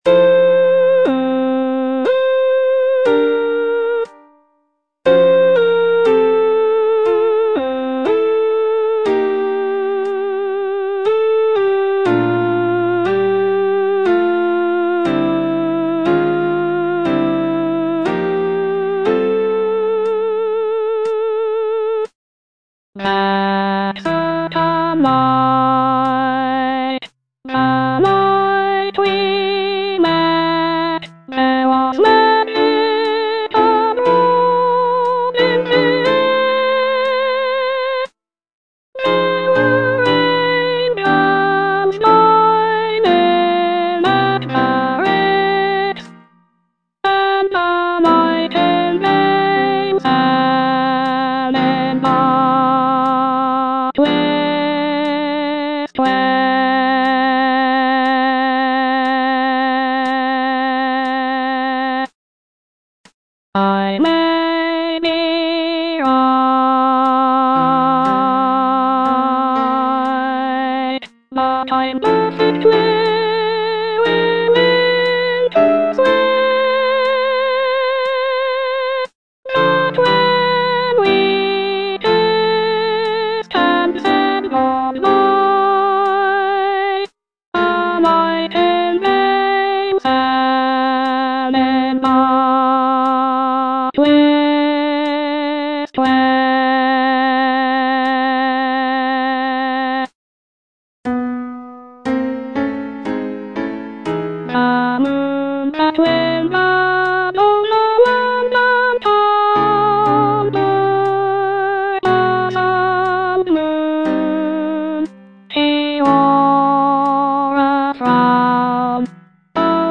Soprano (Voice with metronome)